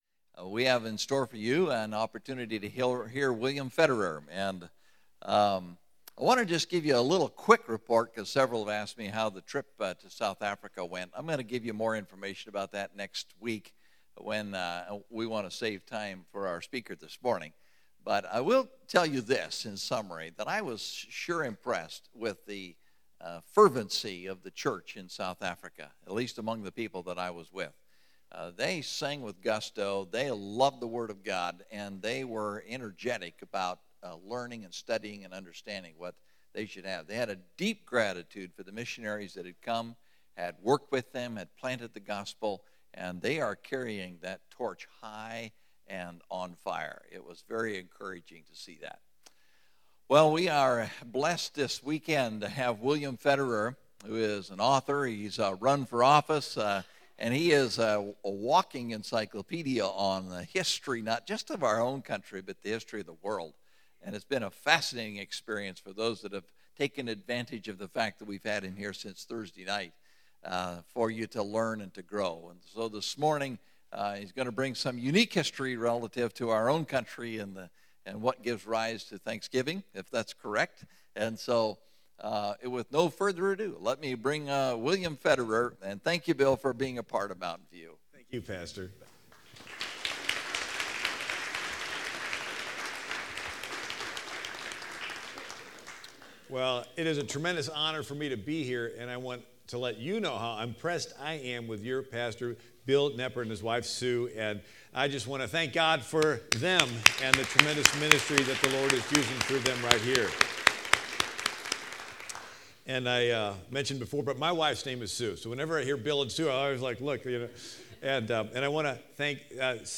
2011 (Sunday Service)Speaker